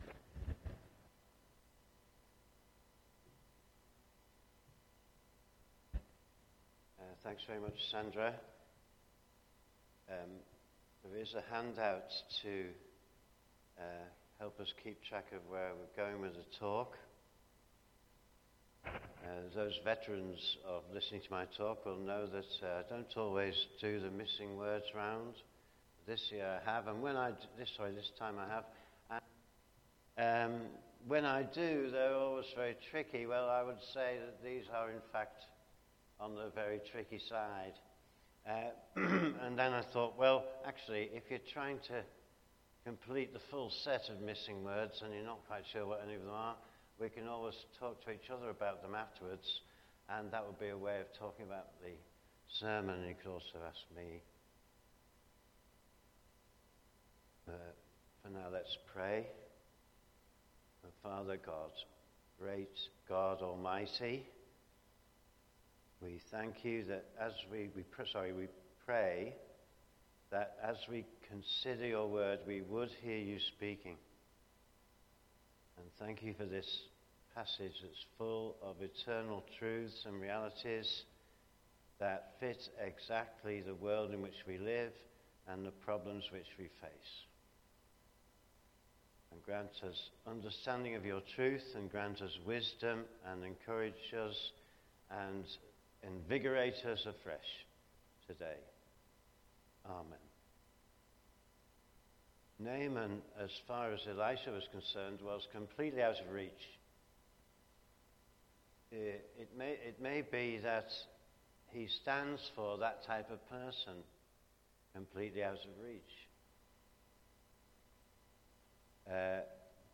Media Library The Sunday Sermons are generally recorded each week at St Mark's Community Church.
Theme: Far off to seeker to believer Sermon